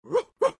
AV_dog_med.ogg